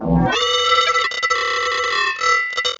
18 Harsh Realm Guitar Tone 2b.wav